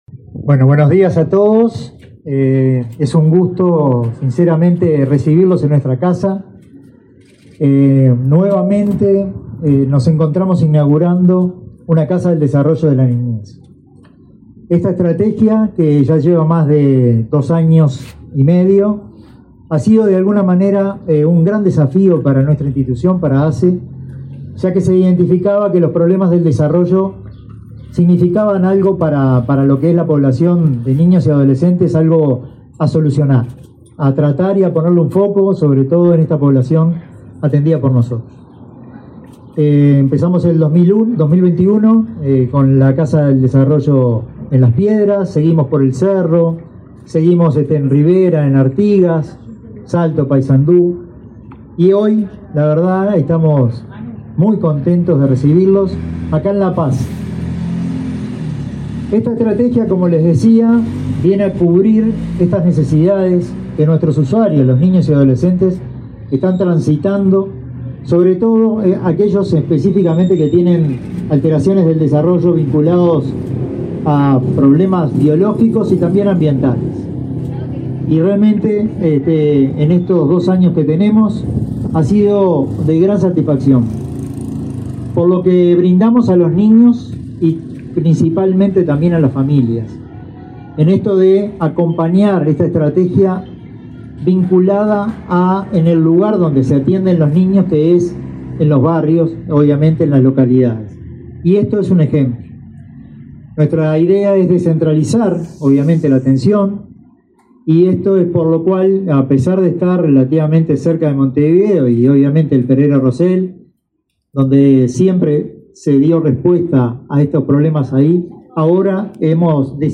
Palabras de autoridades en inauguración de casa del desarrollo de la niñez de La Paz, Canelones
El director del Área de Salud de Niñez y Adolescencia del la Administración de los Servicios de Salud del Estado (ASSE), Ignacio Ascione; el titular